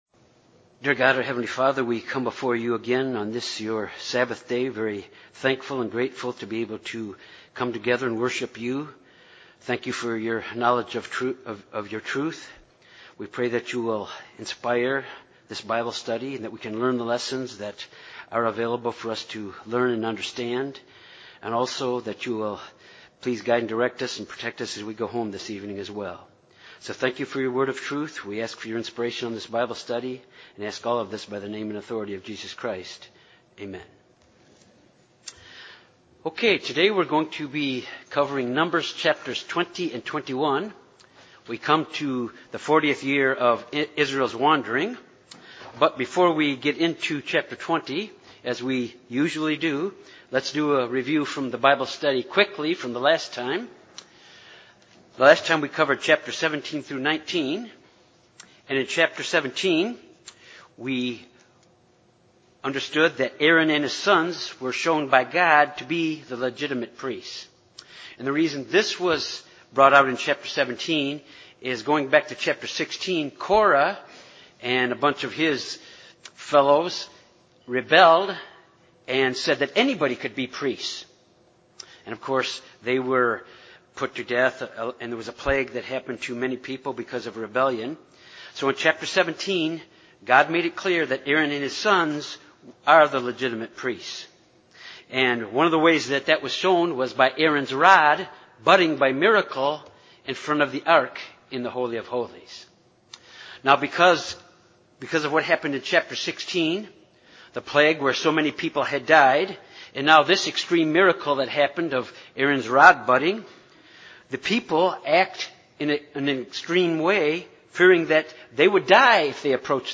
This Bible study begins when Israel has come to the 40th year of wandering in the wilderness and with a brief notice of Miriam’s death, followed a few years later by the death of Aaron and later Moses.
Given in Jonesboro, AR Little Rock, AR Memphis, TN